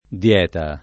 dL$ta] s. f. («regime alimentare»; «assemblea») — oggi di due sillabe, l’una e l’altra voce, ma in origine di tre, com’è richiesto per «assemblea» dall’etimo collegato con , e com’è provato per «regime alimentare» dalle attestaz. nel verso, concordi, fino almeno al ’600: da ch’è sì munta Nostra sembianza via per la dieta [da kk $ SSi mm2nta n0Stra SembL#nZa v&a per la di-$ta] (Dante); Gli è quel che attende a predicar dieta [l’ H kkU%l ke att$nde a ppredik#r di-$ta] (L. Lippi)